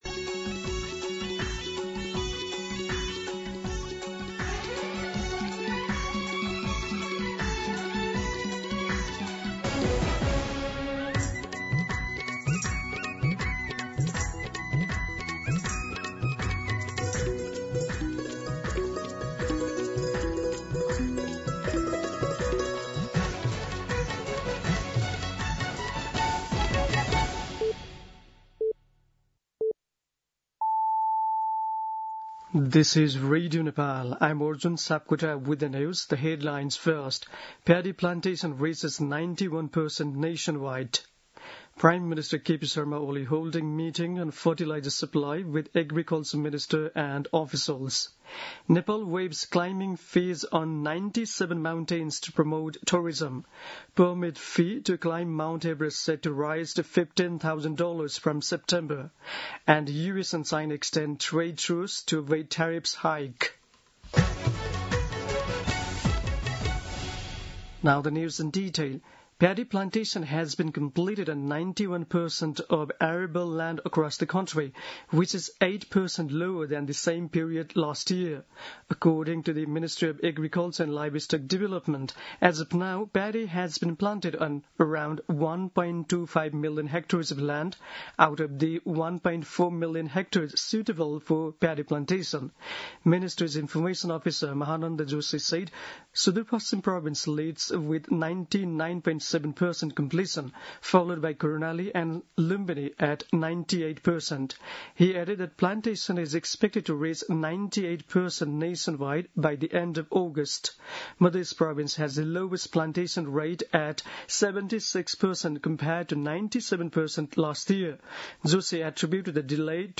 दिउँसो २ बजेको अङ्ग्रेजी समाचार : २७ साउन , २०८२
2pm-English-News-27.mp3